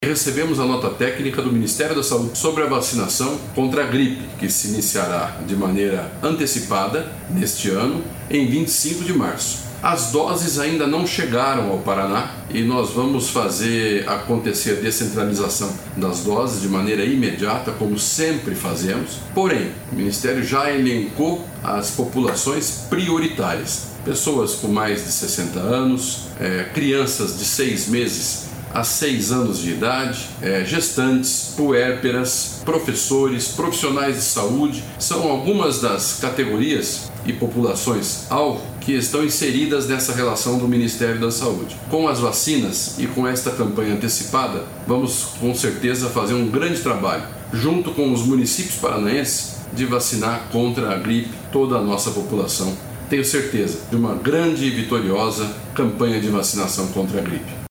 Sonora do secretário da Saúde, Beto Preto, sobre a antecipação da campanha de vacinação contra a gripe em 2024